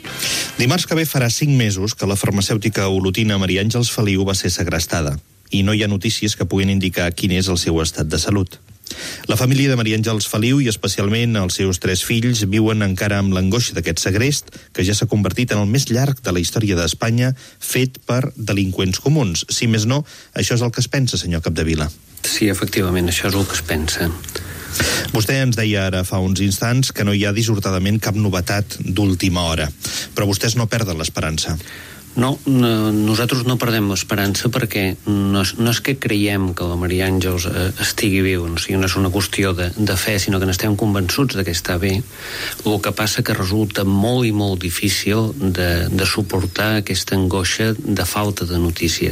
Fragment d'una entrevista
Info-entreteniment